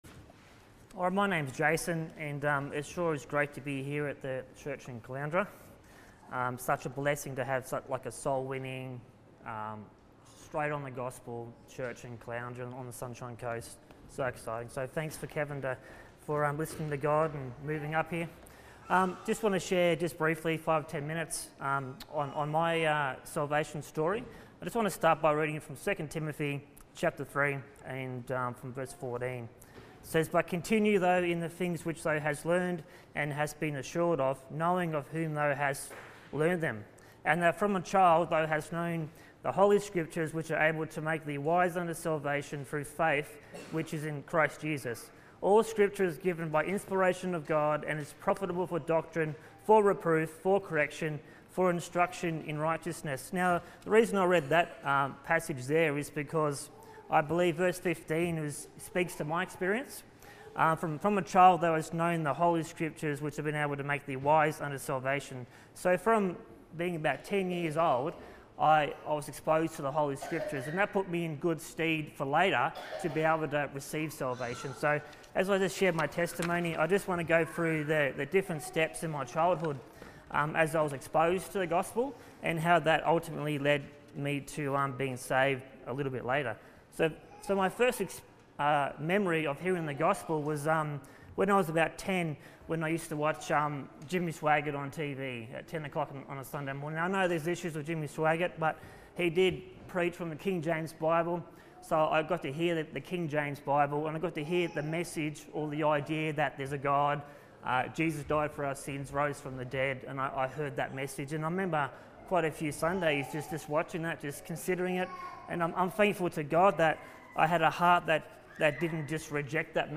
Salvation Testimony